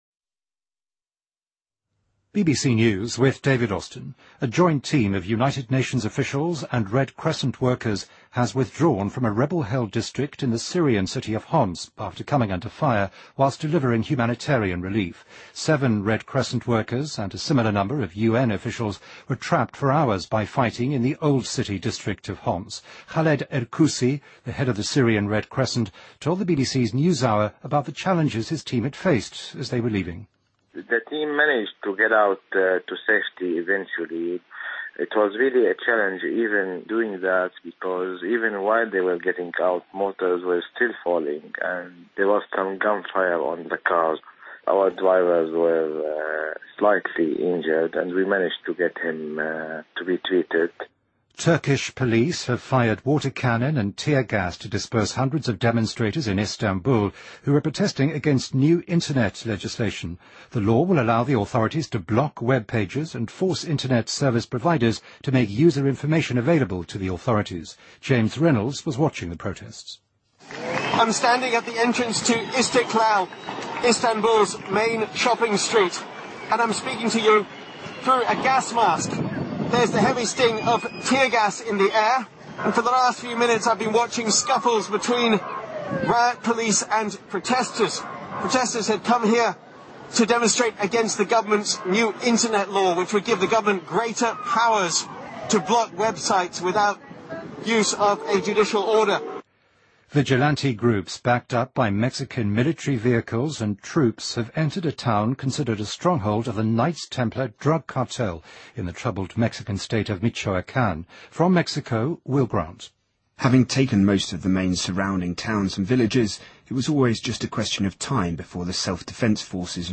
BBC news,2014-02-09